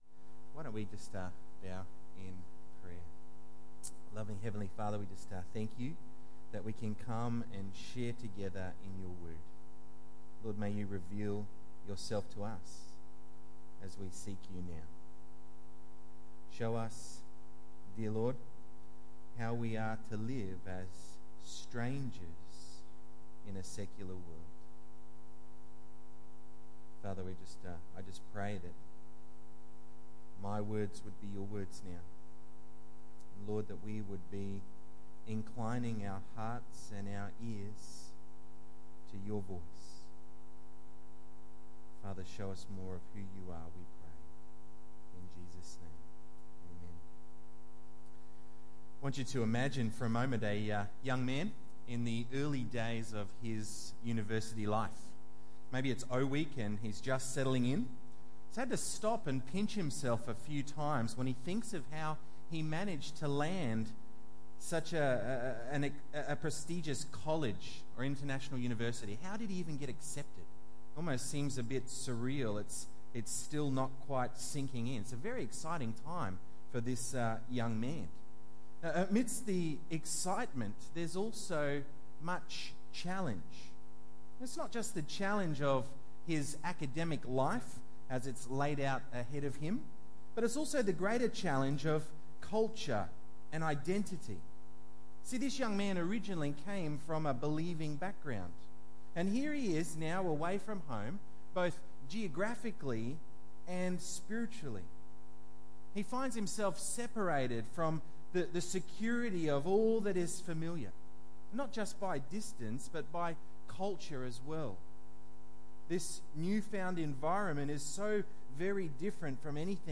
Daniel 1:8-21 Tagged with Sunday Evening